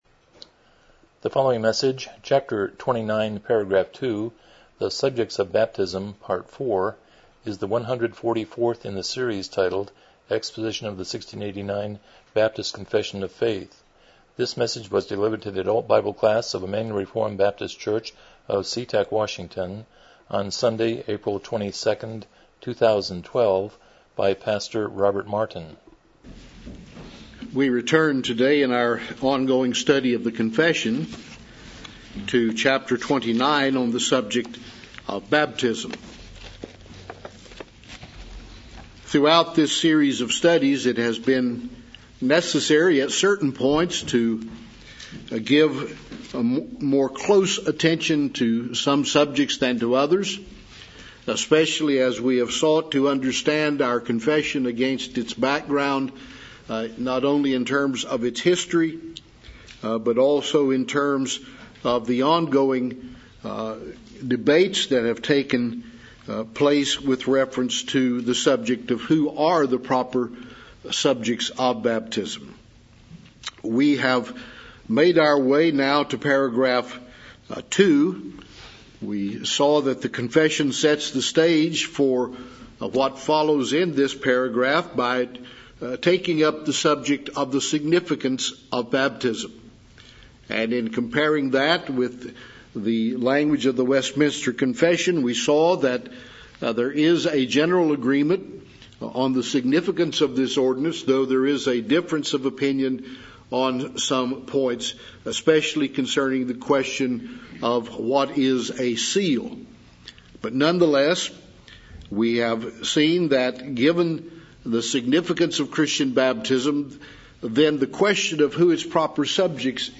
1689 Confession of Faith Service Type: Sunday School « 28 James 5:13-16a 167 Romans 16:20b